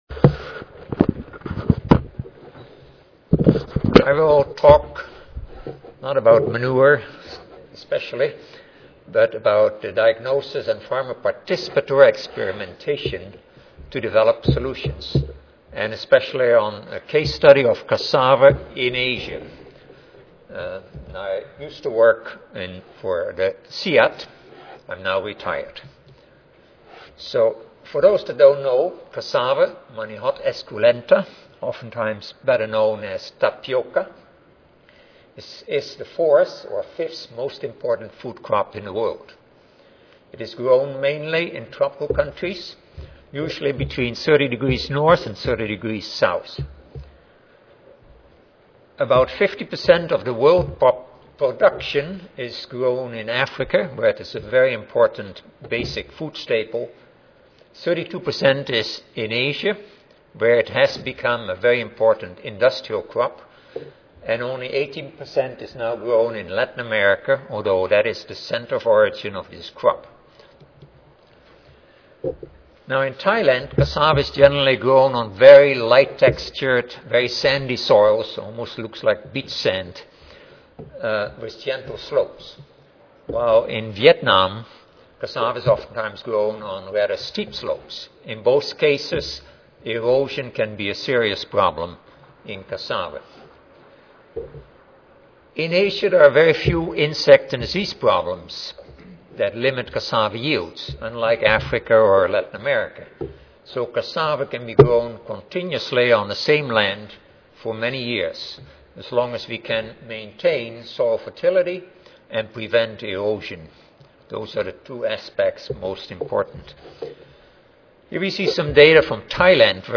Intl Center for Tropical Agriculture Recorded Presentation Audio File